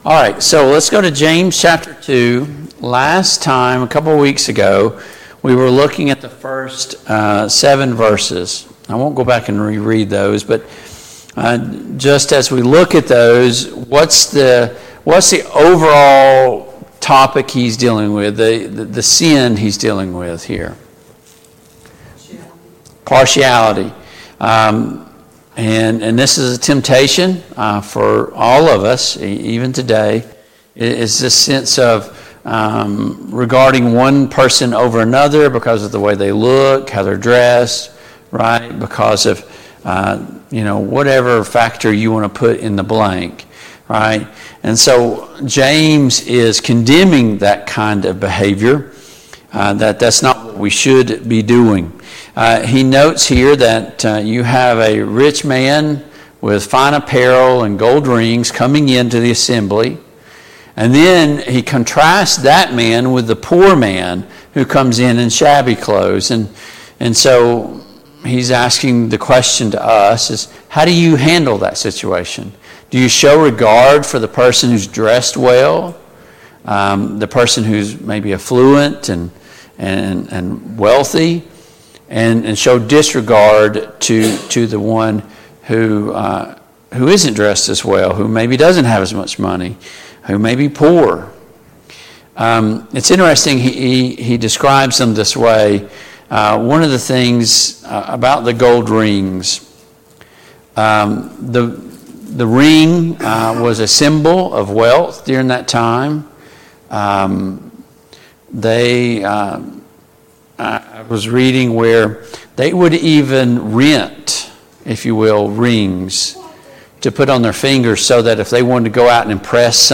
Study of James and 1 Peter and 2 Peter Passage: James 2:1-13 Service Type: Family Bible Hour « Where is the lamb for sacrifice? 8.